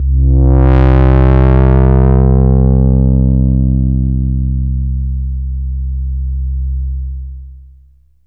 AMBIENT ATMOSPHERES-4 0007.wav